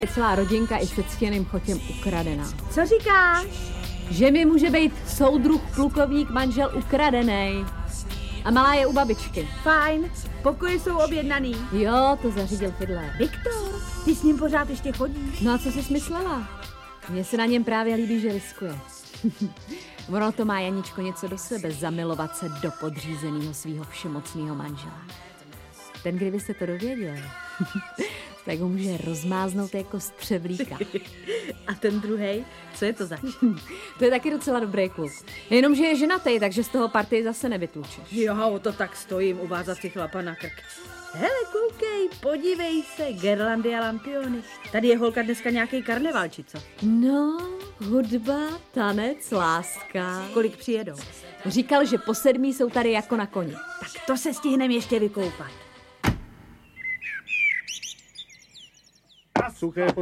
Audiobook
Read: Ivan Řezáč